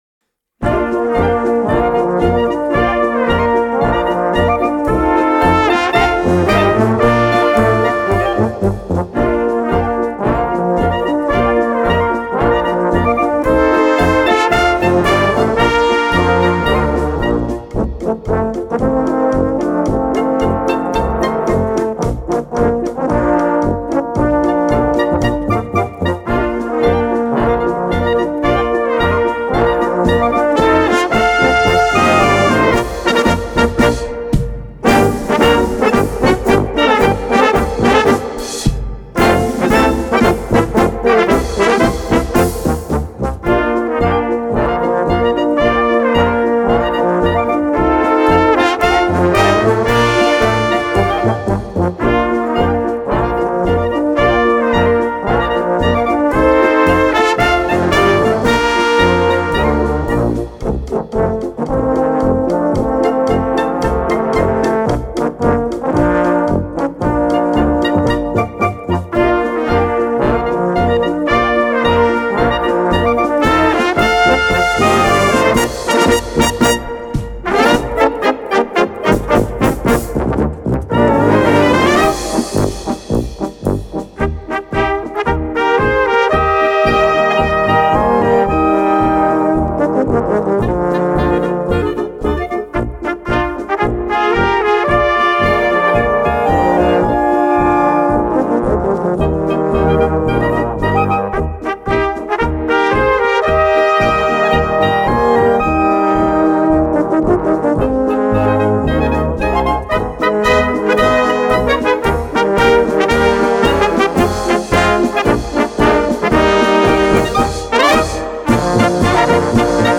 Kategorie Blasorchester/HaFaBra
Unterkategorie Polka
Besetzung Ha (Blasorchester)